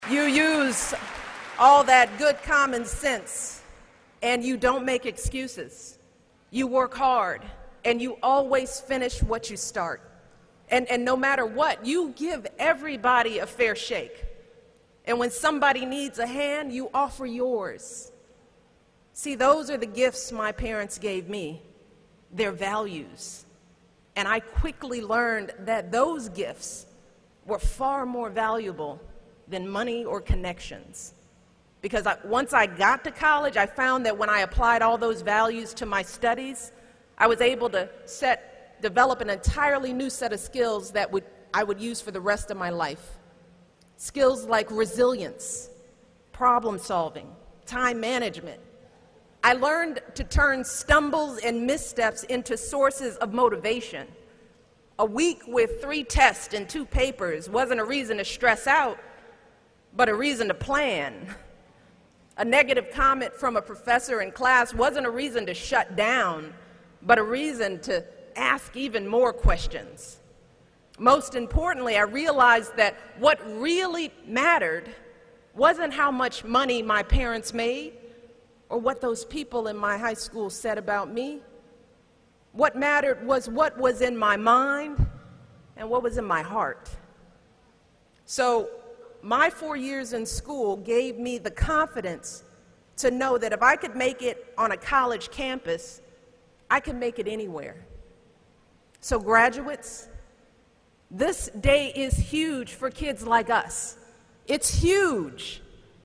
公众人物毕业演讲 第266期:米歇尔2013东肯塔基大学7 听力文件下载—在线英语听力室